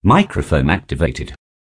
microphone.activated.wav